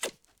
String_release.L.wav